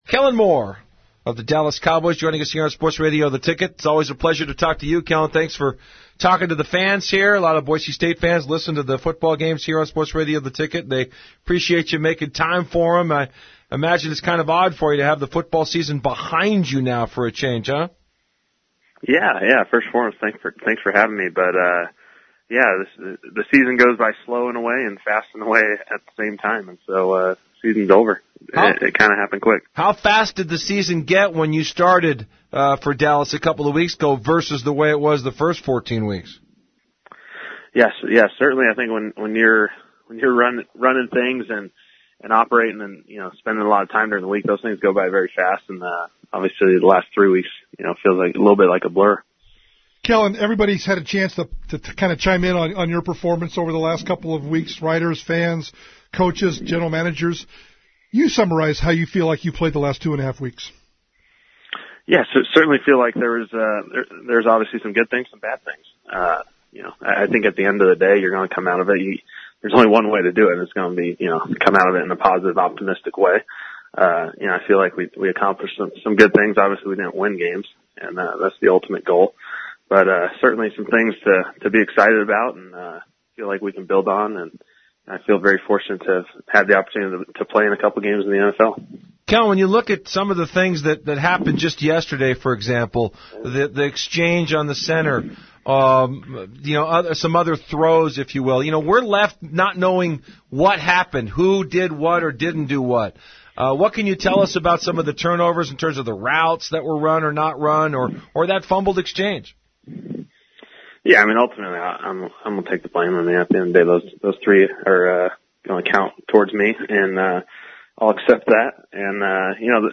Listen to the Kellen Moore interview by clicking this link!